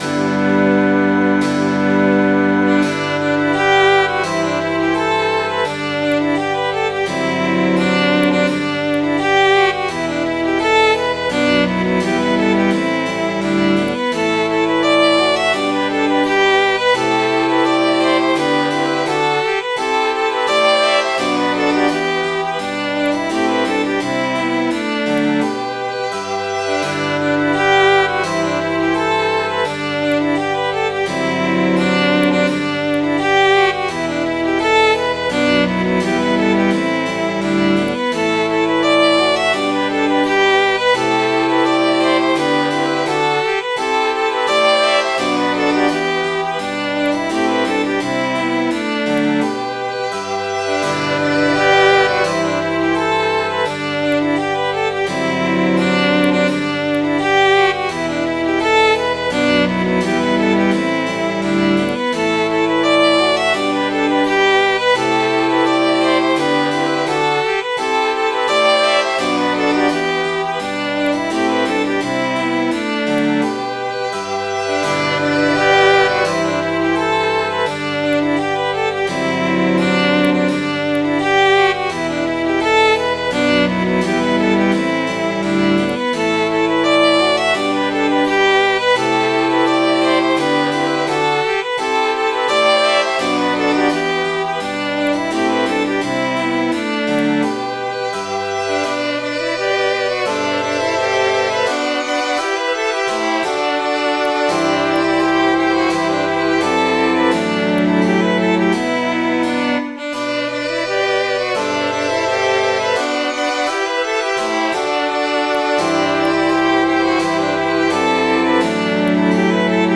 Midi File, Lyrics and Information to Johnny Faa
Variations of this ballad in melody and lyrics at this site are: The Wraggle, Taggle Gypsies, O! and The Gypsy Laddie.